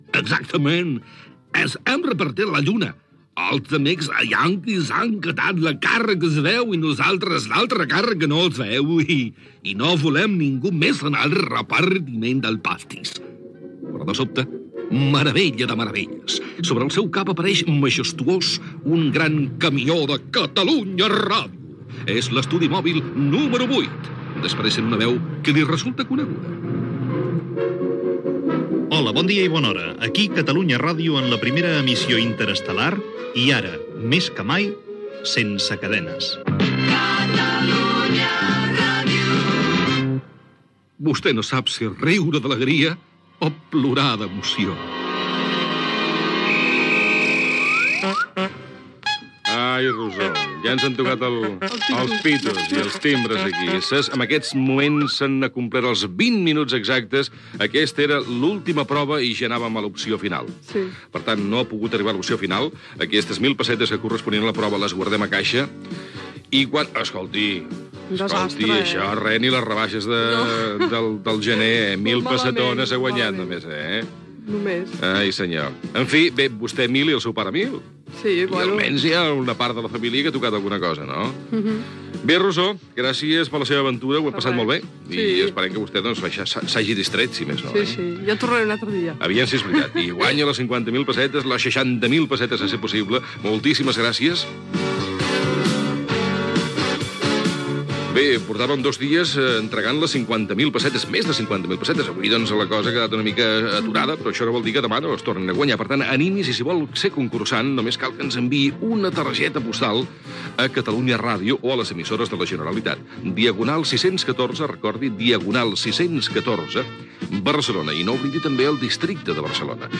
Part final del concurs que ha passat a la Lluna (amb la veu de Josep Cuní), comait de la concursant, adreça de contacte del programa, presentació de la concursant de demà i comiat amb els noms de l'equip del programa
Entreteniment
FM